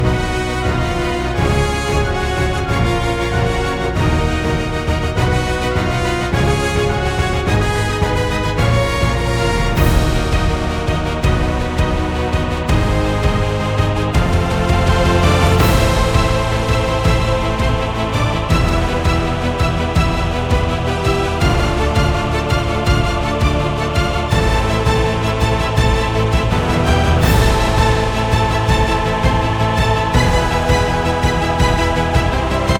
映画音楽風の壮大なオーケストラ
映画のワンシーンを想起させる、壮大でドラマチックなオーケストラ音楽を生成しました。
Epic cinematic orchestral score, grand and sweeping. Powerful brass, soaring strings, dramatic percussion. Evokes a sense of adventure, heroism, or fantasy. Building intensity throughout, with moments of both powerful crescendos and more melodic, emotional passages. Wide dynamic range.
※日本語訳：壮大で広がりのある、映画音楽のようなオーケストラ曲。力強い金管楽器、高らかに響く弦楽器、劇的な打楽器が特徴。冒険、英雄、ファンタジーといった雰囲気をかもし出す。全体を通して盛り上がりを増やしていく、力強い、だんだん強く、より旋律的で感情的な部分が交互に現れる。音の強弱の幅が広い。
細かく指示を出したことで、プロンプトの意図をしっかり反映したオーケストラ音楽が生成されました。